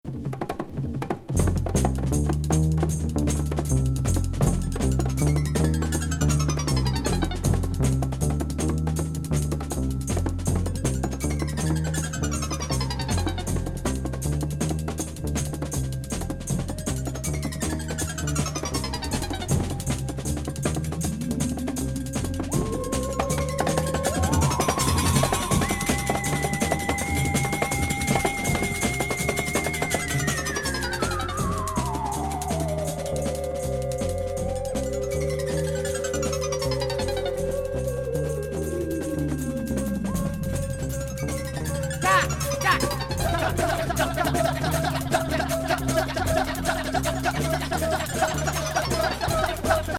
フィールド・レコーディング＋電子音の奇天烈